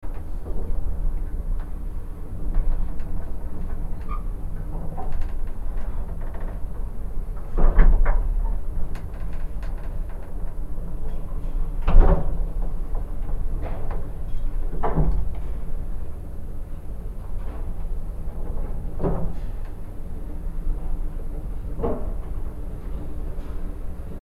Vintage Elevator In Motion Sound Effect – Mechanical Hum And Cabin Creak
Authentic vintage elevator sound effect in continuous motion. Captures the constant mechanical hum of the motor and the rhythmic creak and rattle of the metal cabin on its rails.
Audio loop.
Vintage-elevator-in-motion-sound-effect-mechanical-hum-and-cabin-creak.mp3